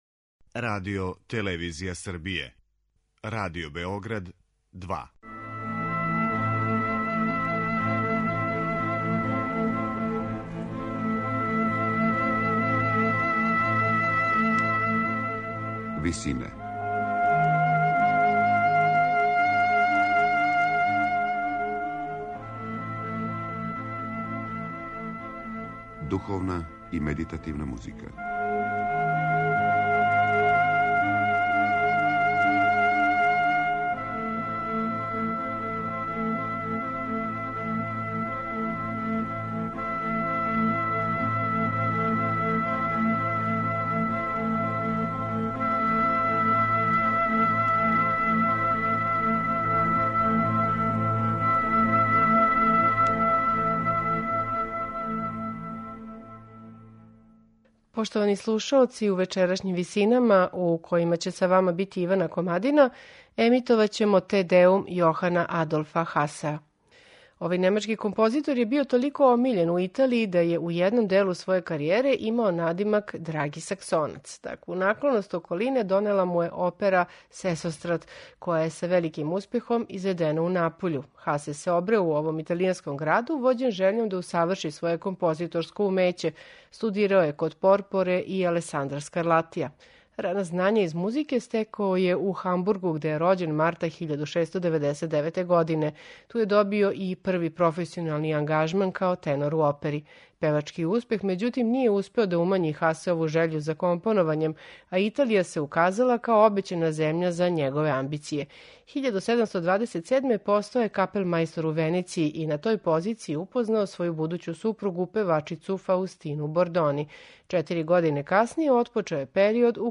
Те Деум је композиција веома сведеног стила, заснована на једном мотиву, замишљеном тако да остави што величанственији утисак у новоизграђеној цркви.
сопран
алт
тенор